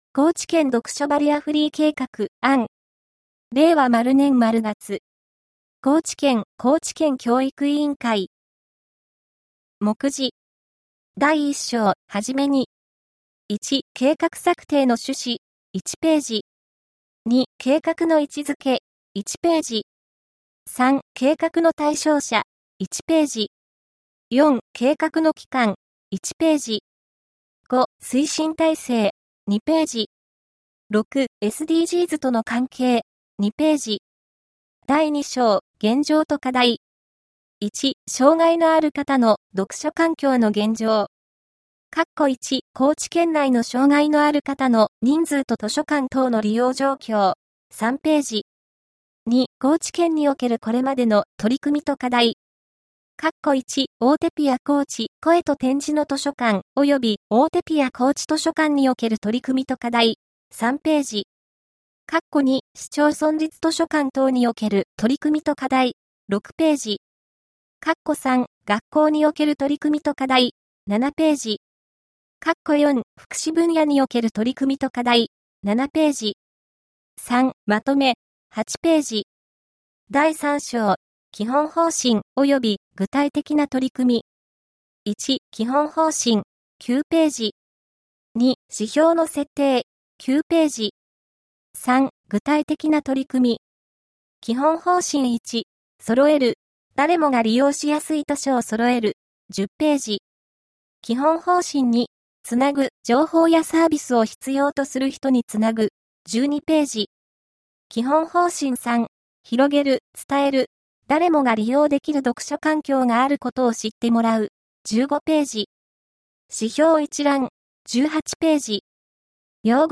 高知県読書バリアフリー計画（案）音声版